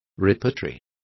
Complete with pronunciation of the translation of repertories.